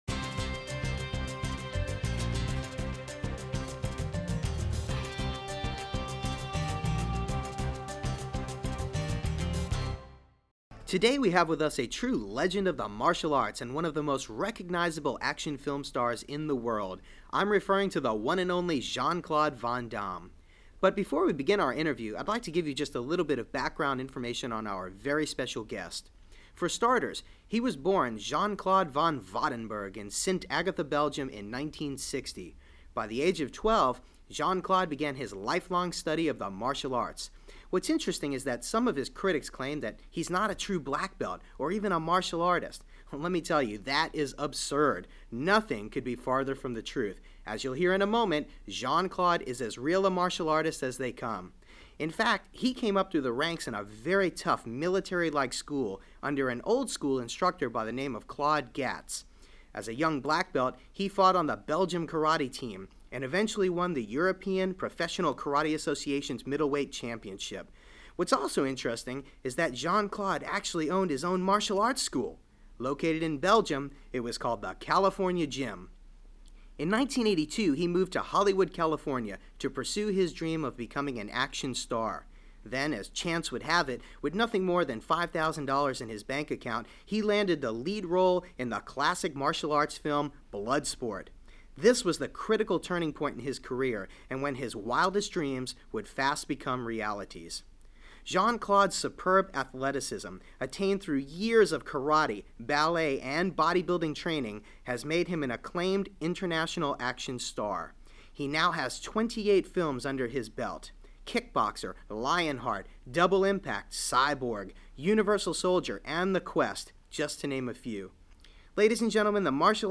A personal interview with the international film star and martial artist. His surprising successes as a school owner and his suggestions for your continuing success in the Martial Arts School Industry.